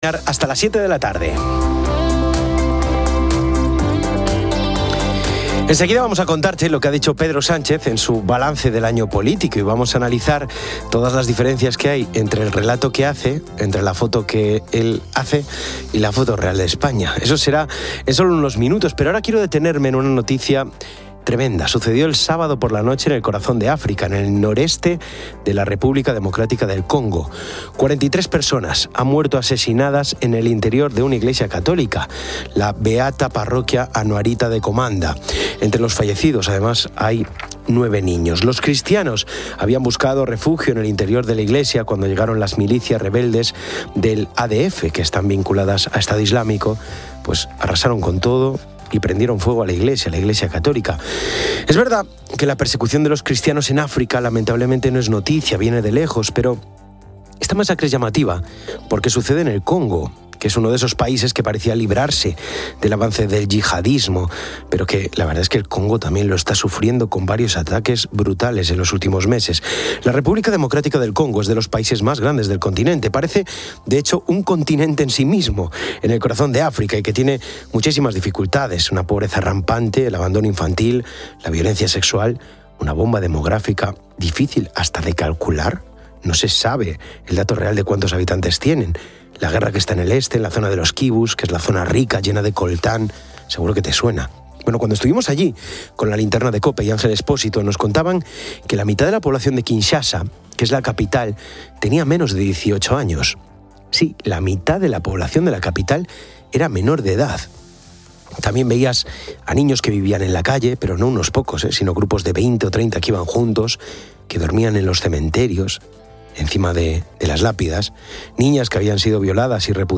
Para ello habla con Santiago Galván, alcalde de la localidad gaditana de Zahara de la Sierra, cuyo ayuntamiento es la primera administración pública que lo pone en marcha.